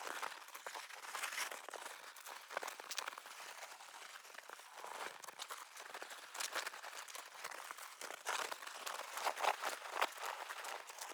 ttr_s_ara_cig_skateDrift.ogg